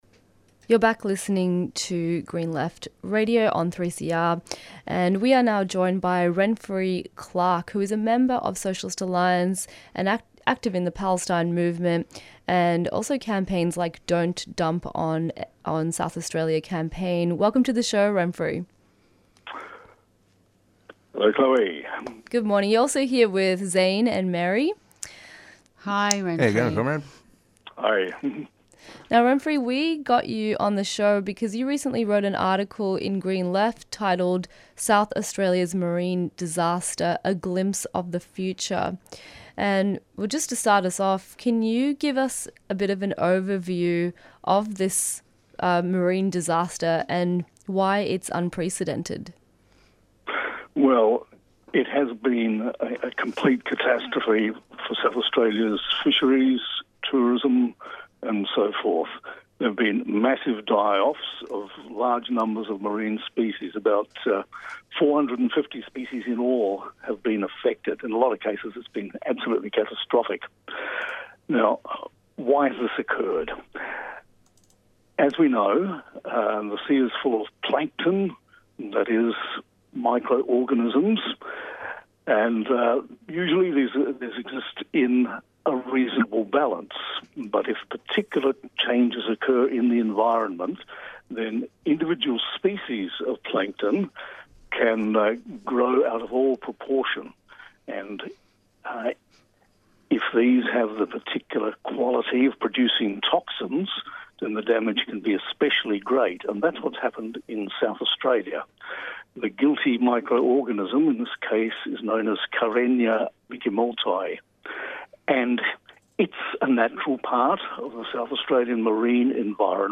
Newsreports
Interviews and Discussions